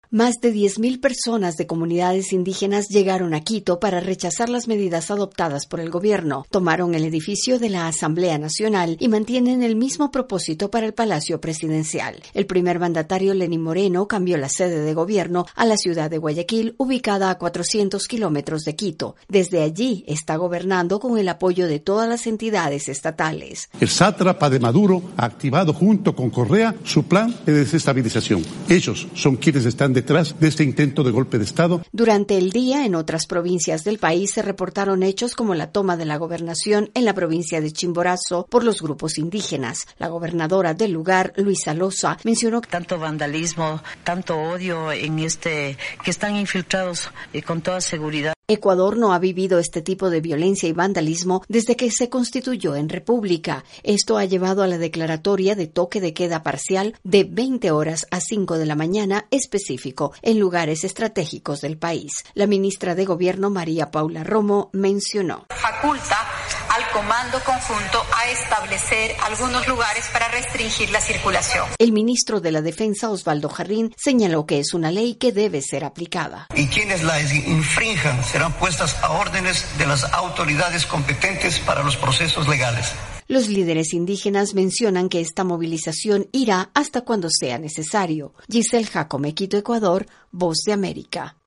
VOA: INFORME DE ECUADOR